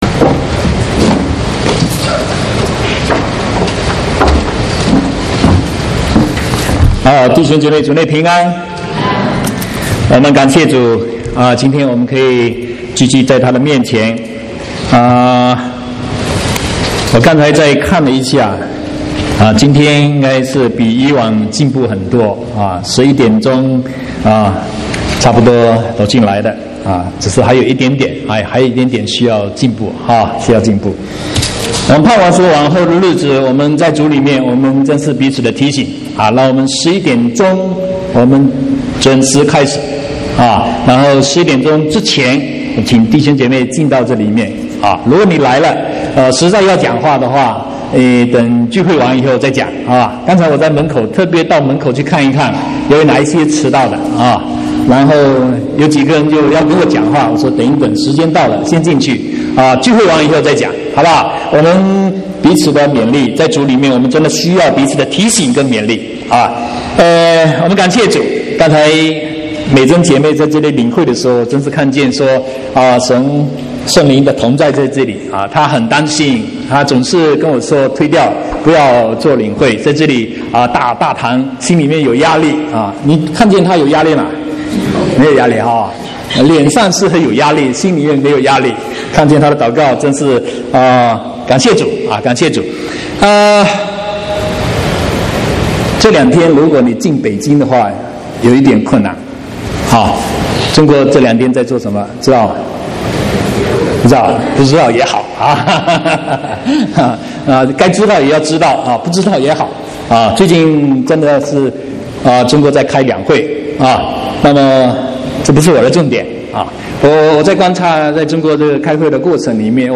7/3/2016國語堂講道